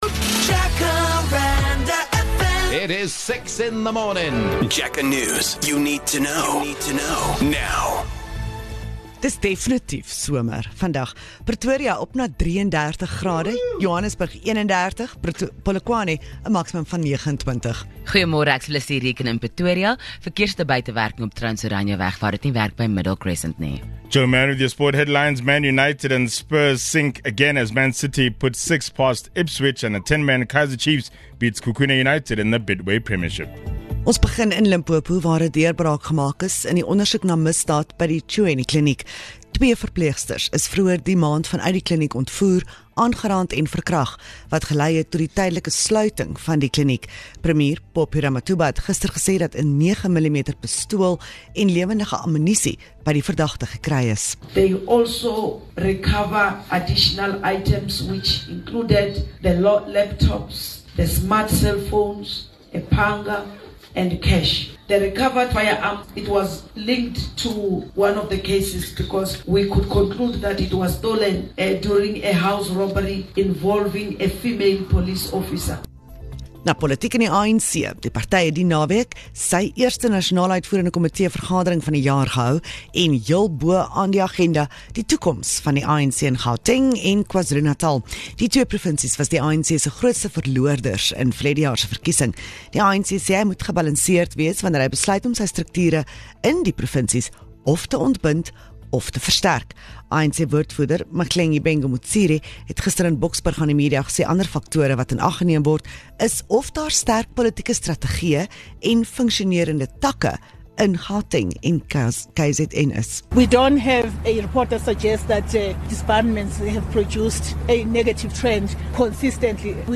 6am News (Afrikaans)